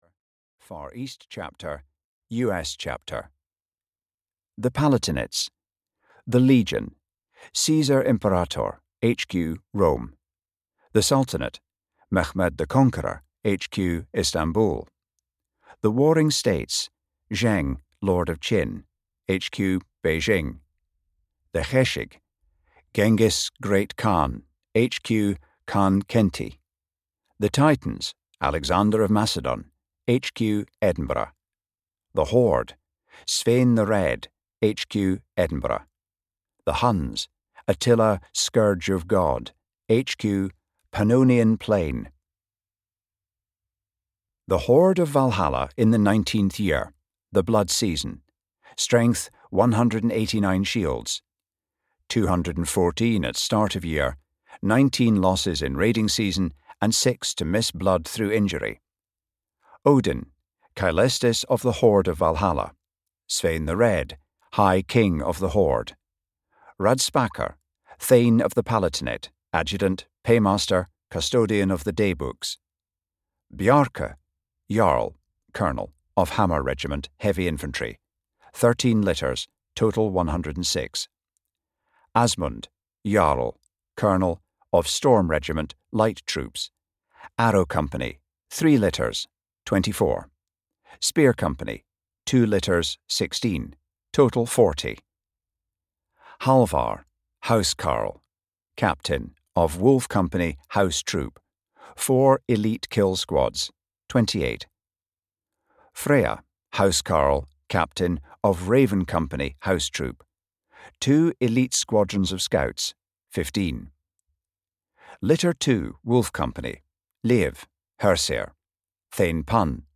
The Blood Isles (EN) audiokniha
Ukázka z knihy